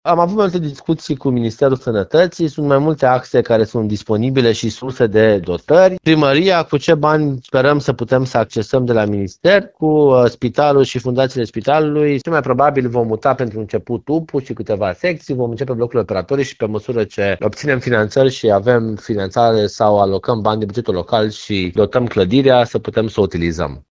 Viceprimarul Timișoarei mai precizează problema dotărilor și echipamentelor este încă nerezolvată. Acesta este motivul pentru care mutarea în noua clădire se va face etapizat.